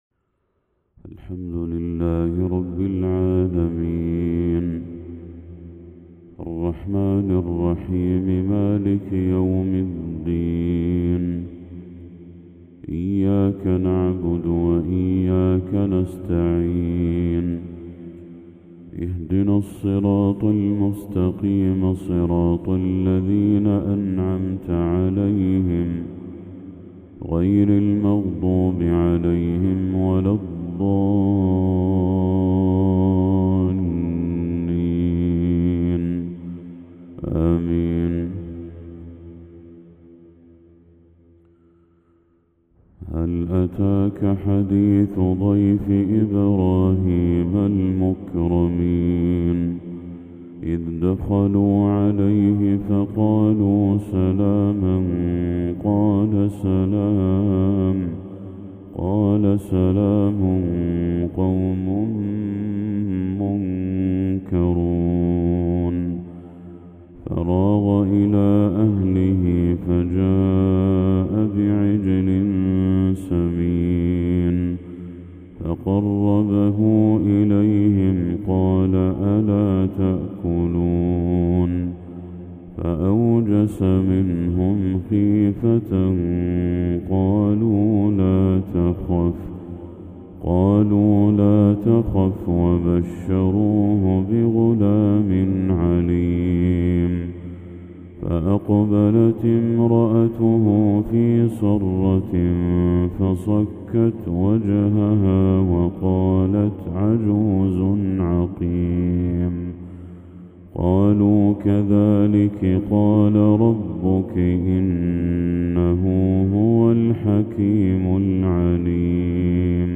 تلاوة هادئة تُسكن القلب للشيخ بدر التركي خواتيم سورة الذاريات | فجر 19 ذو الحجة 1445هـ > 1445هـ > تلاوات الشيخ بدر التركي > المزيد - تلاوات الحرمين